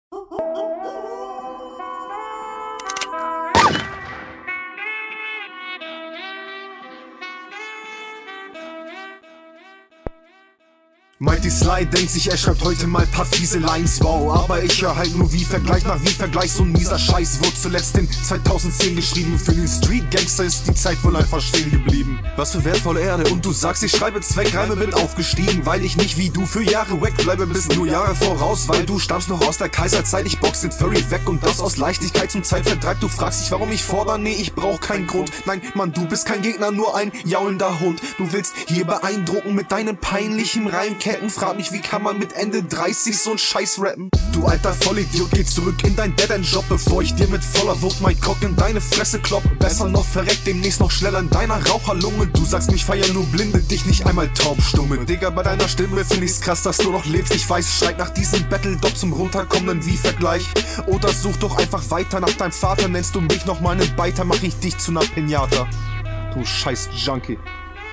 Sehr nices Intro.